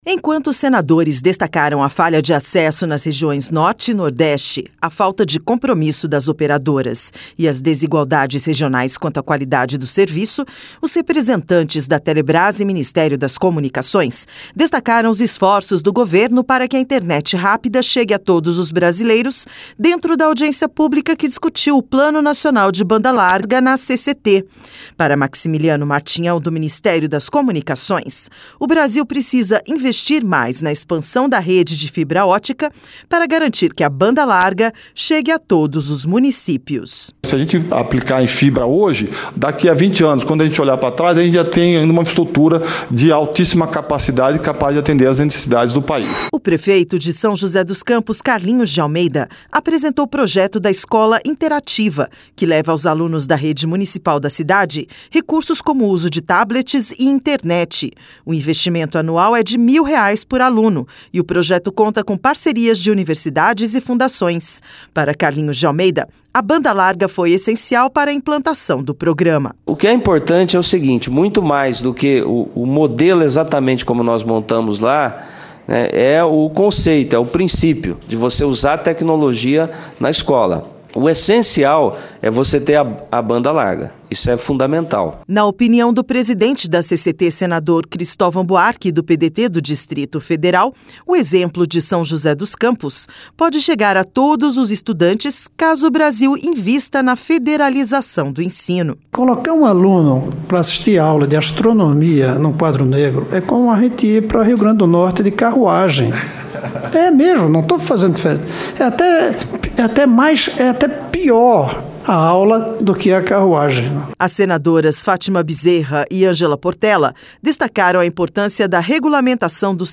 LOC: A UNIVERSALIZAÇÃO DA BANDA LARGA FOI TEMA DE UMA AUDIÊNCIA PÚBLICA NA COMISSÃO DE CIÊNCIA E TECNOLOGIA DO SENADO NESTA TERÇA FEIRA.